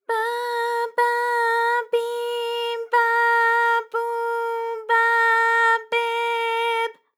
ALYS-DB-001-JPN - First Japanese UTAU vocal library of ALYS.
ba_ba_bi_ba_bu_ba_be_b.wav